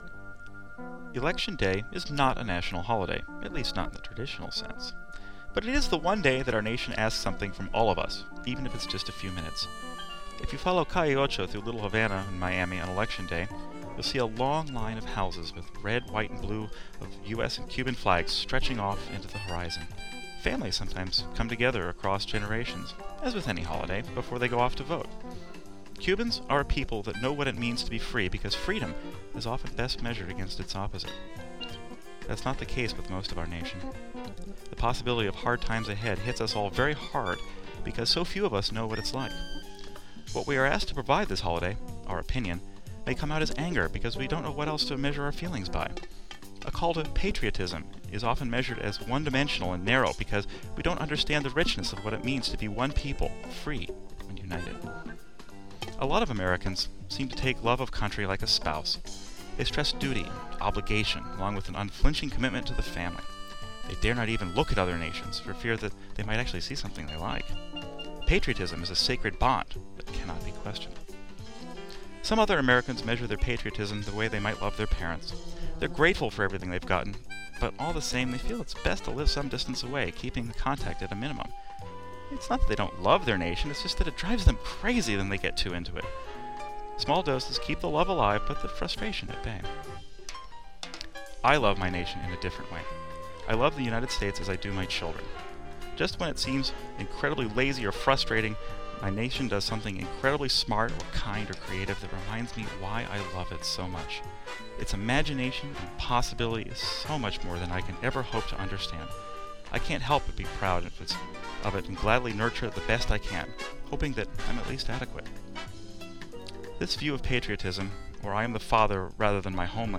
The music is “Liberty City” by Jaco Pastorius.